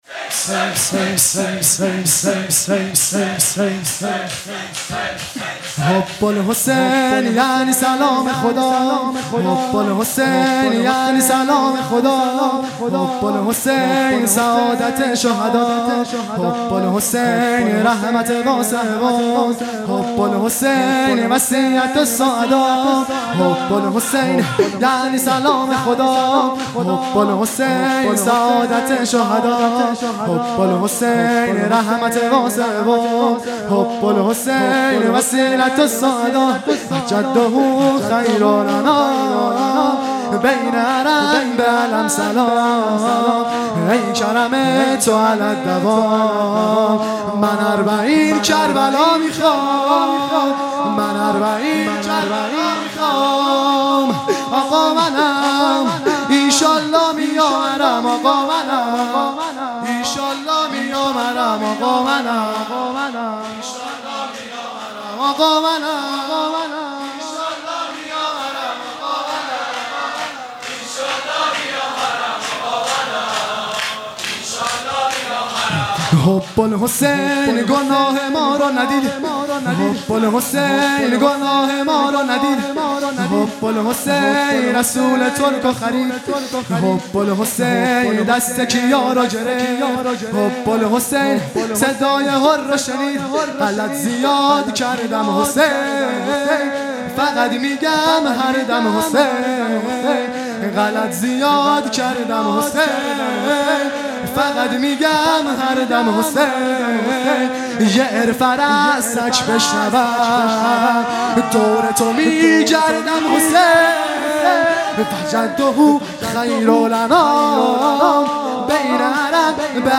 خیمه گاه - هیئت بچه های فاطمه (س) - شور | حب الحسین یعنی سلام خدا | 11 مرداد ۱۴۰۱